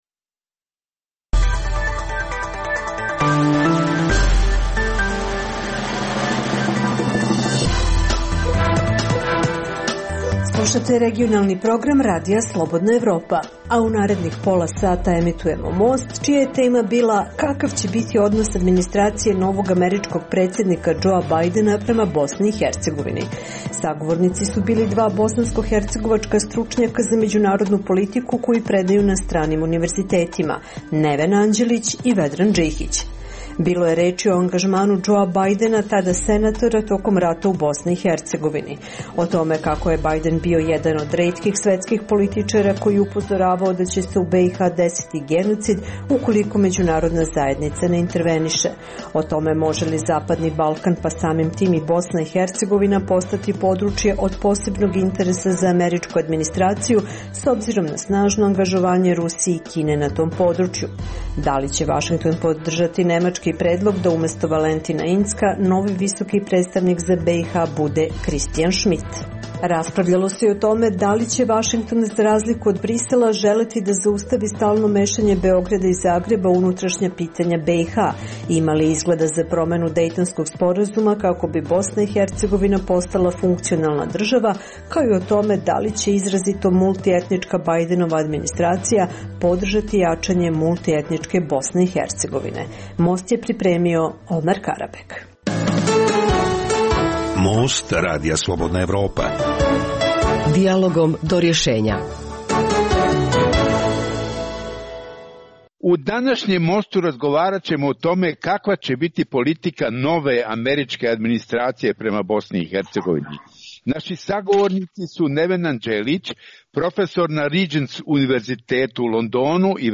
Dijaloška emisija o politici, ekonomiji i kulturi